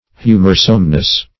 \Hu"mor*some*ness\